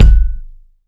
Kick (51).wav